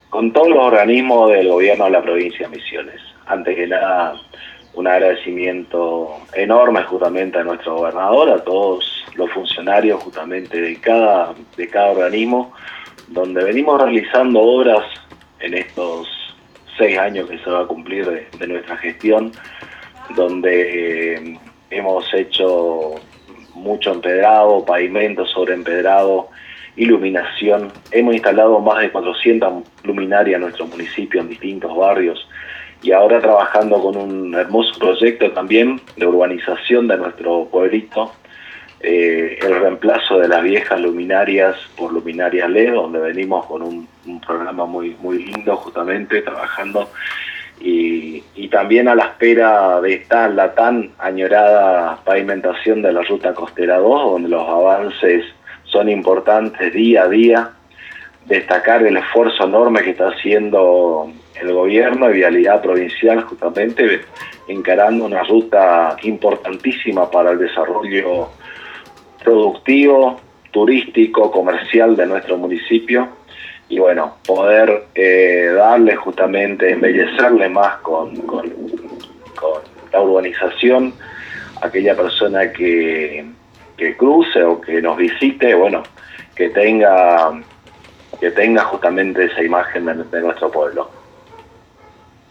El intendente de Tres Capones Ramón Gerega dialogo en forma exclusiva con la A.N.G. y Éxito FM de Apóstoles donde informó sobre la realización del Rally Tres Capones los días 29, 30 y 31 de octubre y el trabajo que se está realizando para que esta actividad se desarrolle con la mayor seguridad posible y con los protocolos de salud.